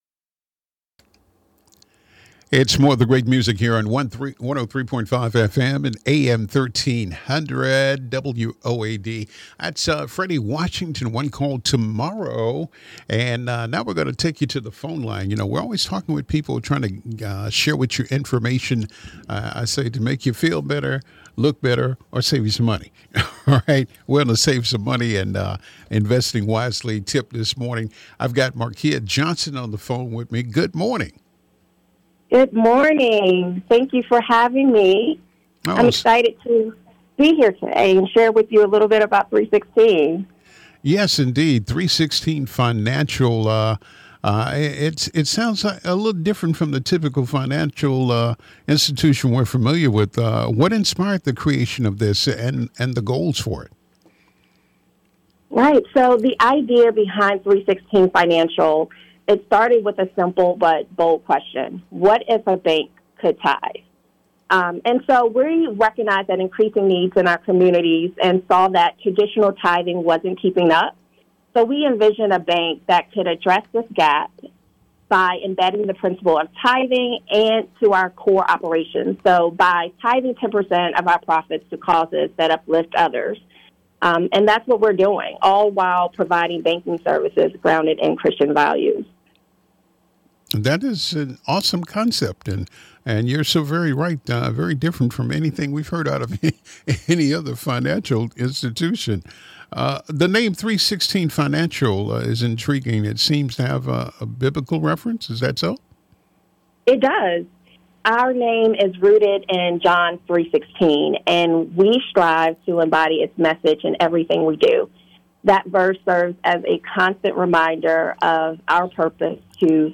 We were honored to be featured on WOAD 1300 AM in Jackson, Mississippi—a trusted voice in urban gospel radio.